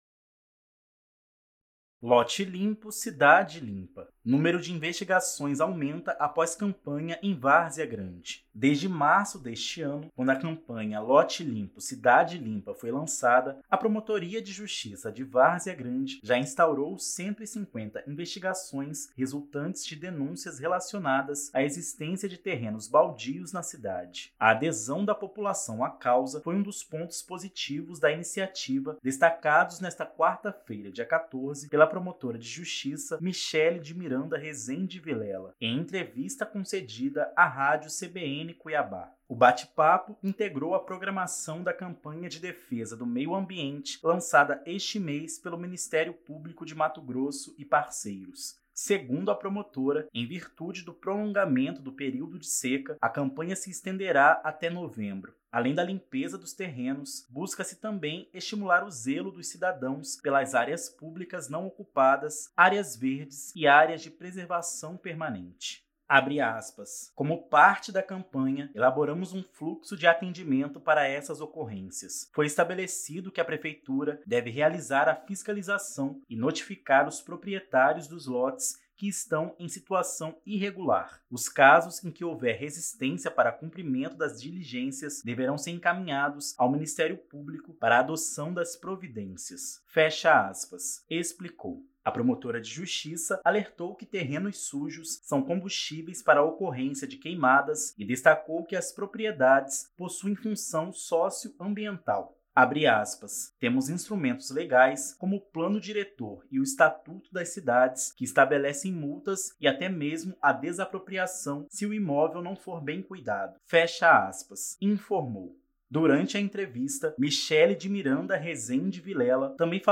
Desde março deste ano, quando a campanha “Lote limpo, cidade limpa” foi lançada, a Promotoria de Justiça de Várzea Grande já instaurou 150 investigações resultantes de denúncias  relacionadas à existência de terrenos baldios na cidade. A adesão da população à causa foi um dos pontos positivos da iniciativa destacados nesta quarta-feira (14) pela promotora de Justiça Michelle de Miranda Rezende Villela, em entrevista concedida à Rádio CBN Cuiabá.